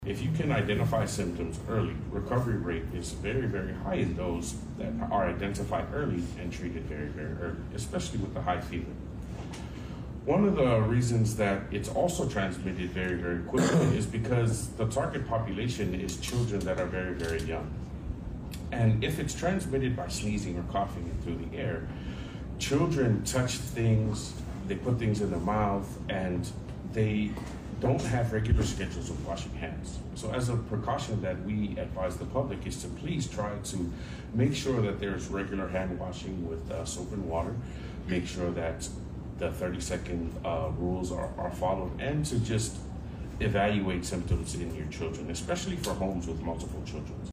DOH held a press conference today to announce the immunization campaign, and gave advice to parents about identifying symptoms of measles and prevention measures.